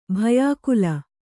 ♪ bhayākula